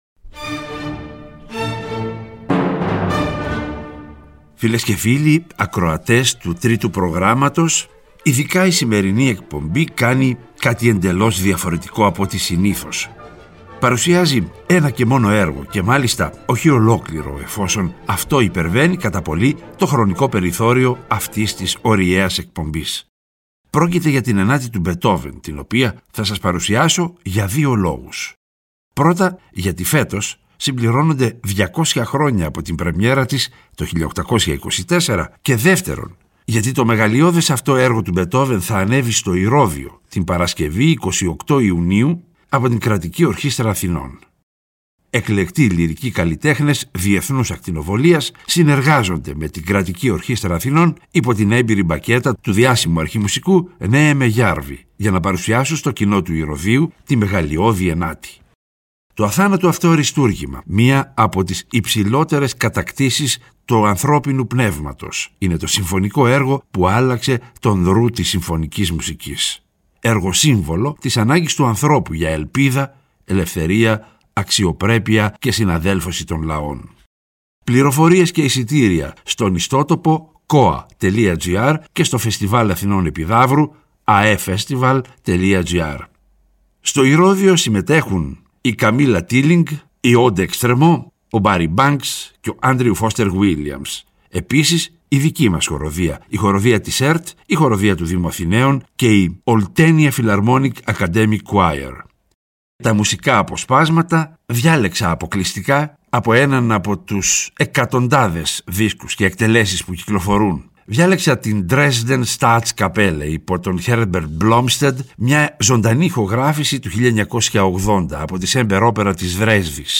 Αποκλειστικά ένα και μόνον έργο, κι αυτό όχι ολόκληρο, λόγω του περιορισμένου χρόνου μιας ωριαίας εκπομπής: Η 9η Συμφωνία του Μπετόβεν που παρουσιάζεται για δύο λόγους. Πρώτον, γιατί εφέτος συμπληρώνονται 200 χρόνια από την πρεμιέρα της το 1824.